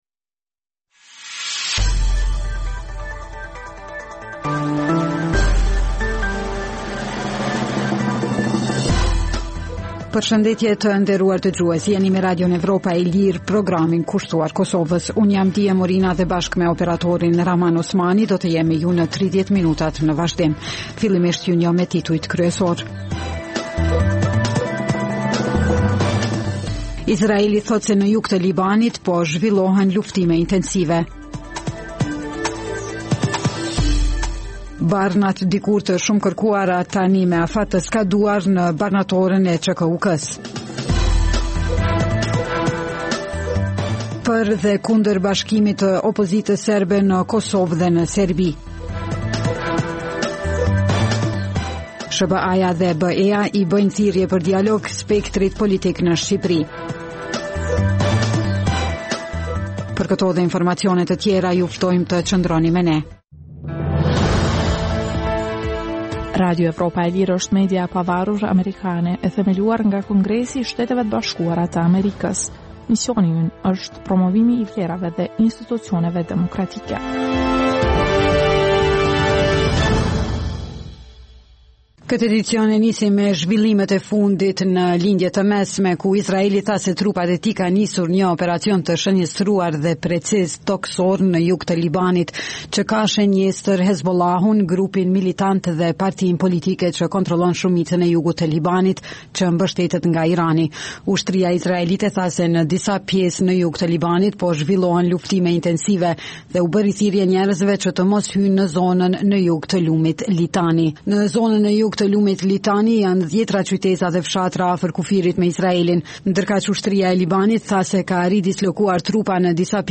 Emisioni i orës 16:00 është rrumbullakësim i zhvillimeve ditore në Kosovë, rajon dhe botë. Rëndom fillon me buletinin e lajmeve dhe vazhdon me kronikat për zhvillimet kryesore të ditës. Në këtë edicion sjellim edhe intervista me analistë vendës dhe ndërkombëtarë.